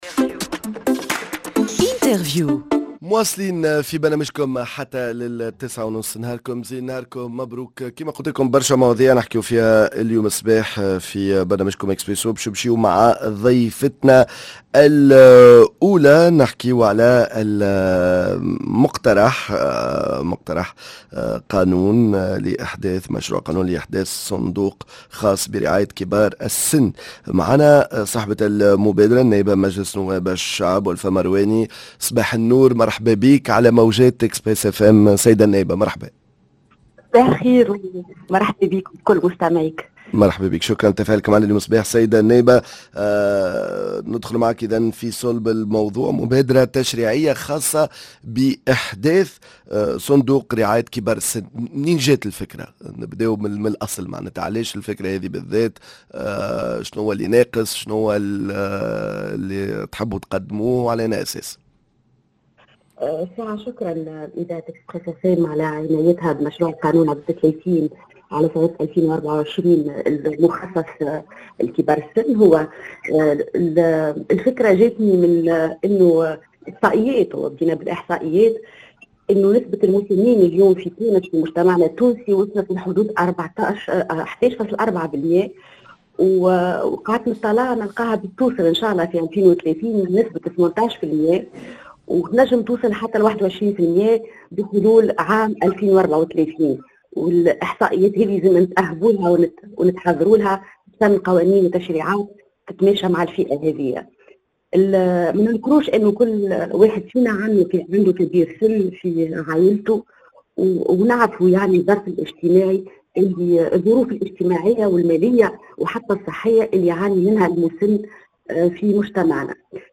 النائب بمجلس نواب الشعب ألفة المرواني صاحبة المبادرة التشريعية الخاصة بإحداث صندوق رعاية كبار السن مشروع قانون لإحداث صندوق خاص برعاية كبار السن